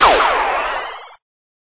bomb-get.mp3